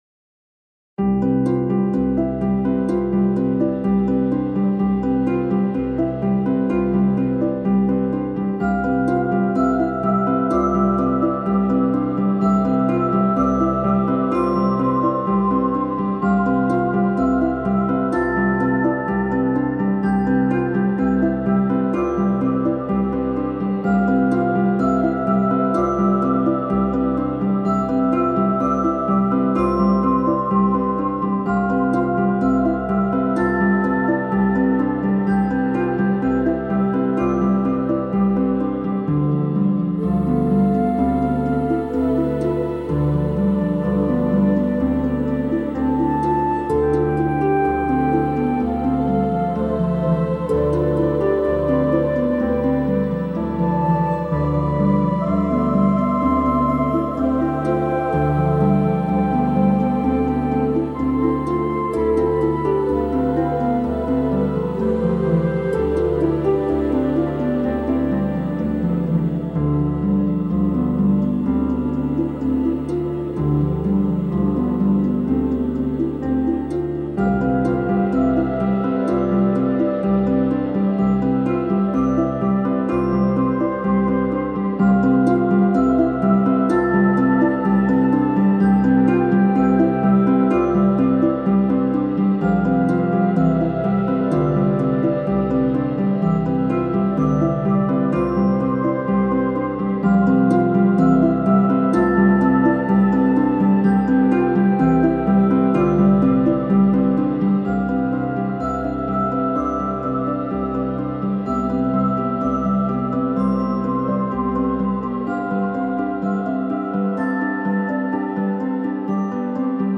Minimalistic track with a fantasy tone for dynamic realms.